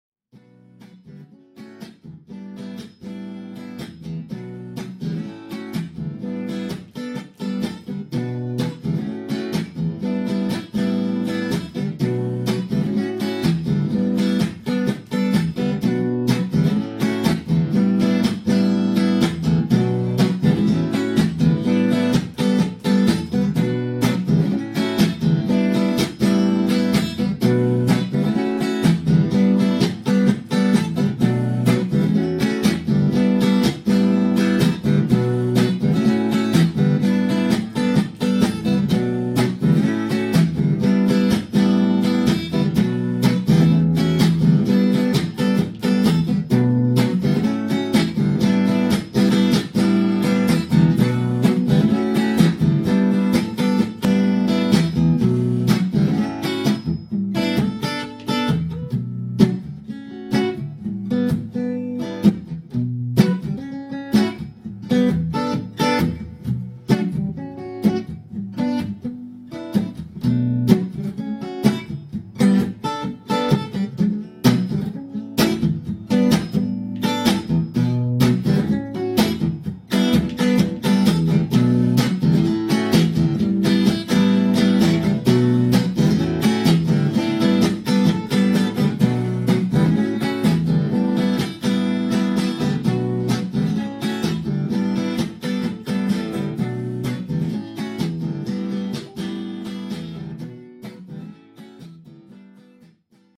new riff for a new song idea ...